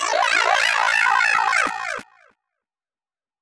Techno / Voice / VOICEFX244_TEKNO_140_X_SC2(R).wav
1 channel